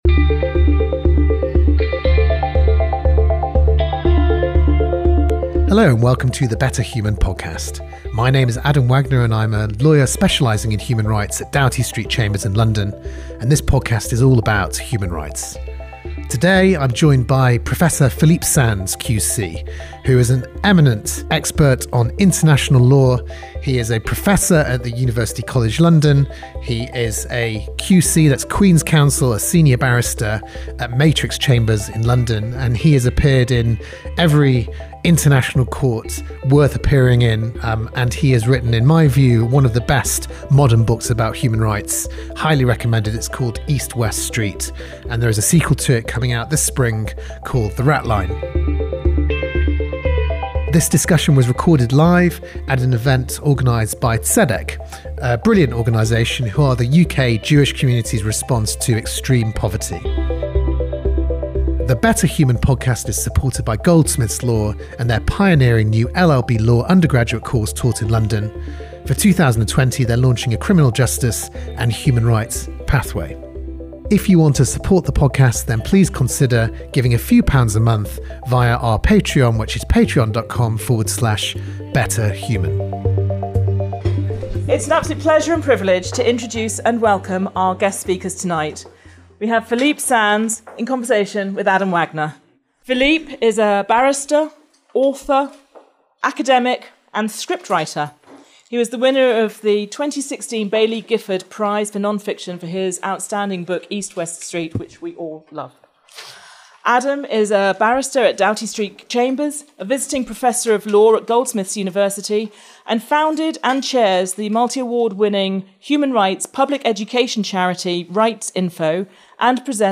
In this discussion, recorded live at an even organised by Tzedek, we discuss how Philippe's wonderful book East West Street came to be written, why the lessons of the 1940s are still fundamentally important today and what we can do to honour them as living memory of the Holocaust disappears.